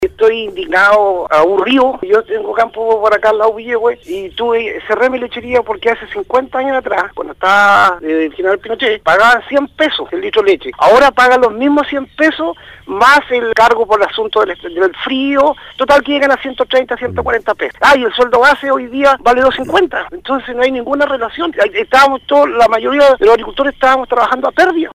En conversación con Radio Sago, uno de los  agricultores afectados, manifestó que resulta inconcebible que los precios que se pagaban hace 30 años, no hayan variado mucho, provocando un grave daño a la economía regional. Agrega que la crisis lo obligó a cerrar su lechería y dejar a varios trabajadores sin su fuente laboral.